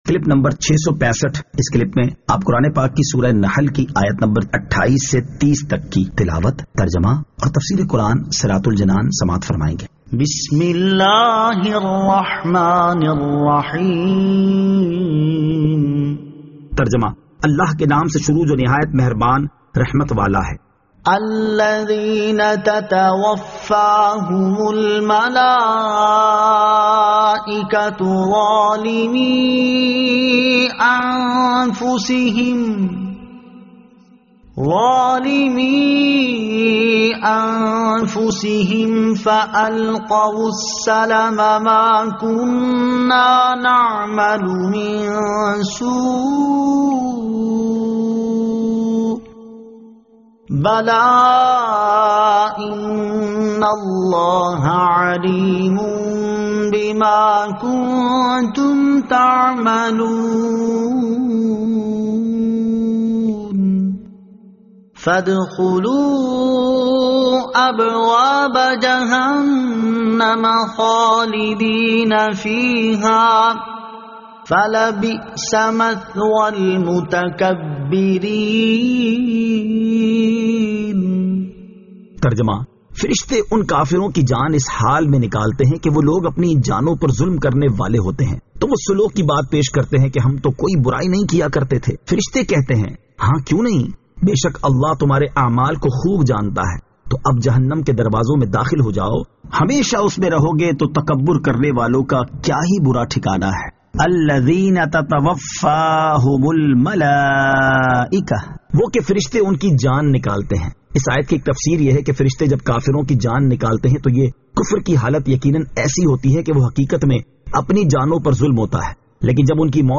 Surah An-Nahl Ayat 28 To 30 Tilawat , Tarjama , Tafseer